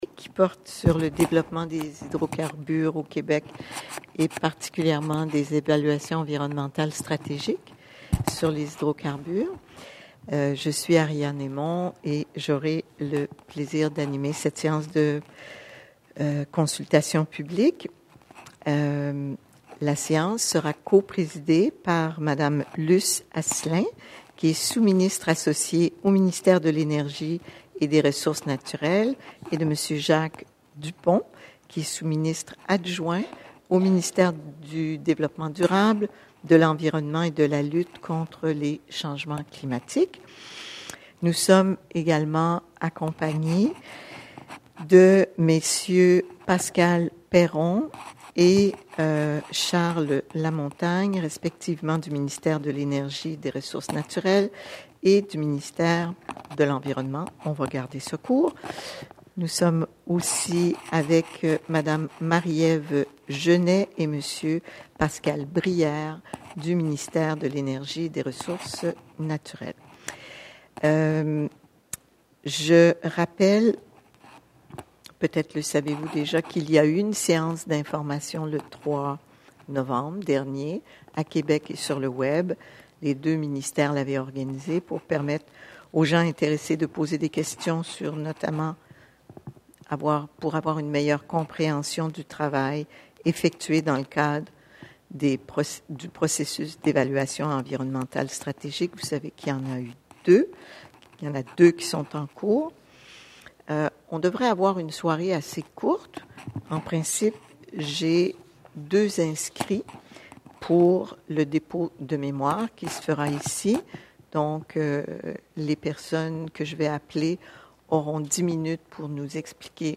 Des rencontres de consultation ont été tenues, en novembre 2015, à Montréal, à Bécancour, à Québec, à Gaspé, à Anticosti, aux Îles-de-la-Madeleine et à Sept-Îles. Au total, environ 330 personnes ont participé sur place aux séances publiques.